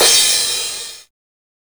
CRASH01   -R.wav